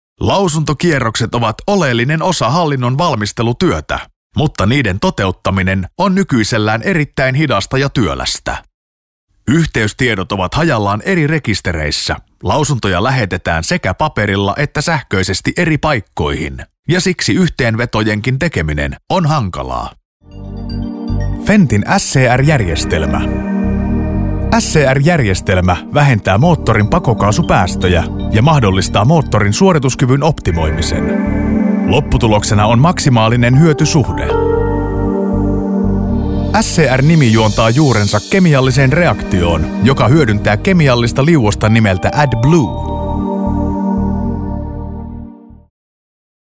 Native finnish professional voiceover artist with a warm versatile voice for all kind of voiceover work.
Sprechprobe: Industrie (Muttersprache):
I record using a very highend recording gear and signal chain.